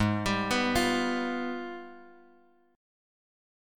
G#mM13 chord